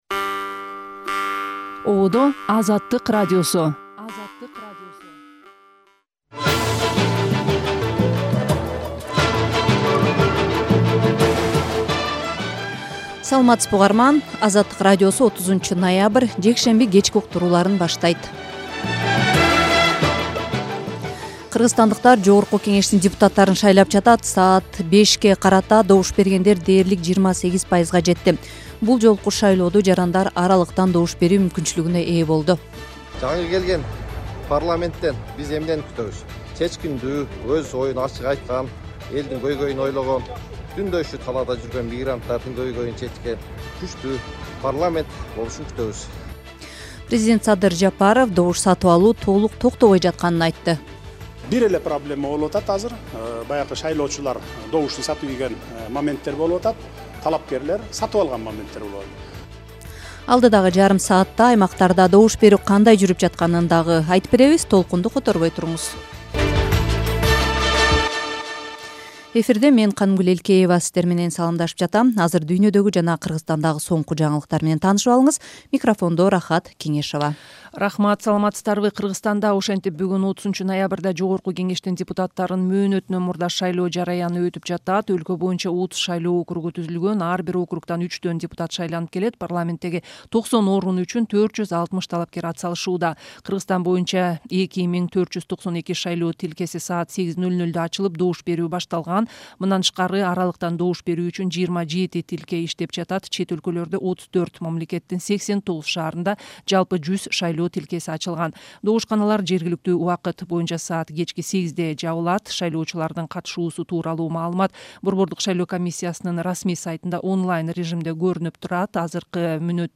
Радио эфир | 30.11.2025 | Жапаров добуш сатып алуу толук токтобой жатканын айтты